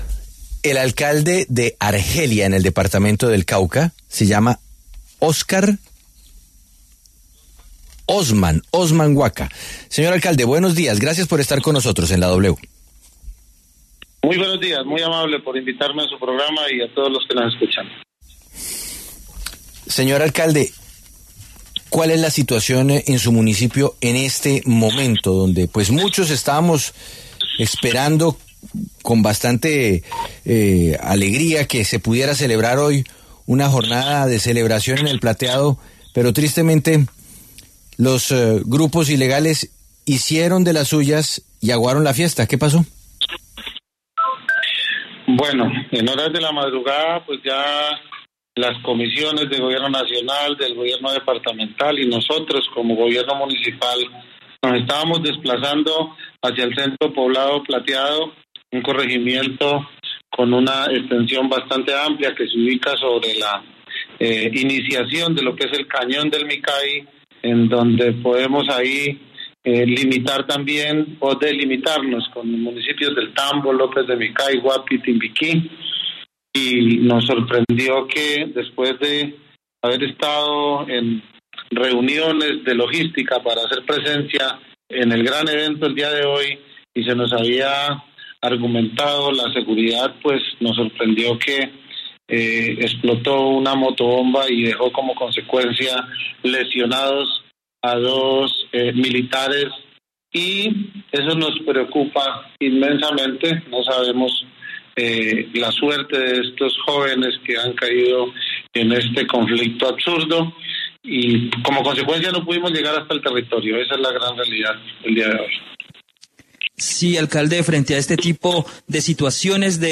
El alcalde de Argelia, Cauca, Osman Guaca pasó por los micrófonos de la W tras el ataque terrorista en su localidad.